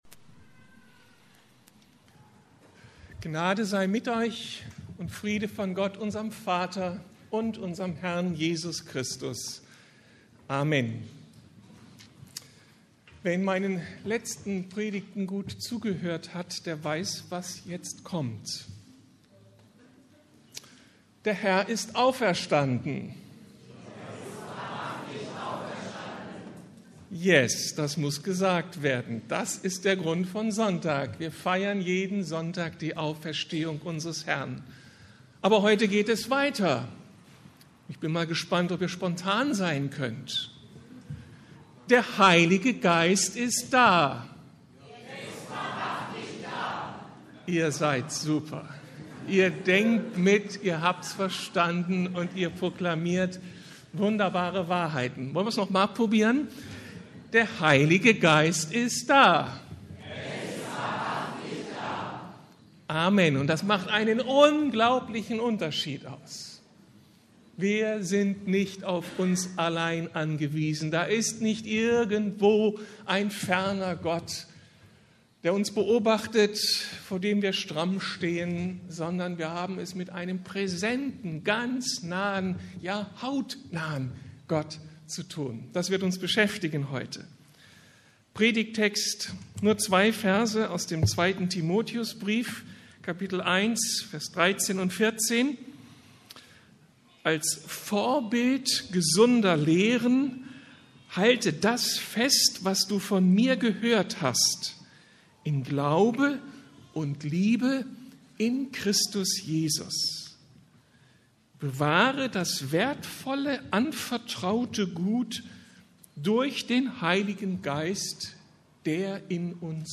Der unspektakuläre Dienst des Heiligen Geistes ~ Predigten der LUKAS GEMEINDE Podcast